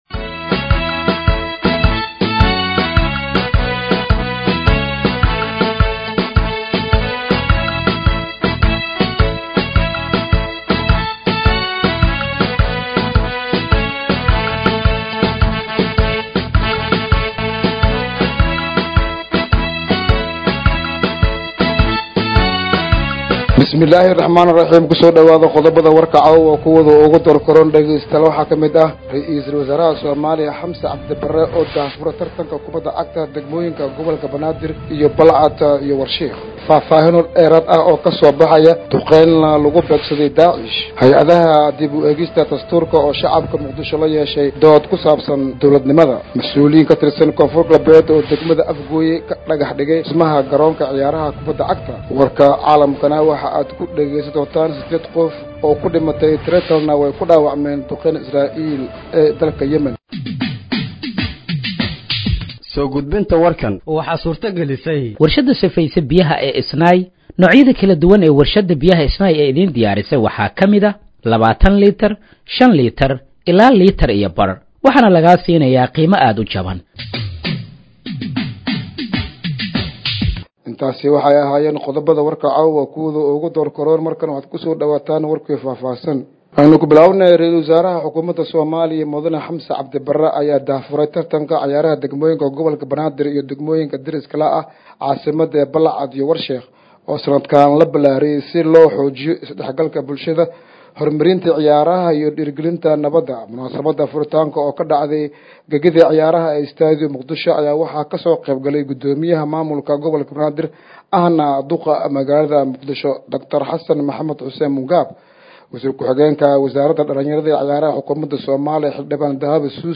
Dhageeyso Warka Habeenimo ee Radiojowhar 26/09/2025